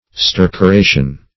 Search Result for " stercoration" : The Collaborative International Dictionary of English v.0.48: Stercoration \Ster`co*ra"tion\, n. [L. stercoratio, from stercorare to dung.] Manuring with dung.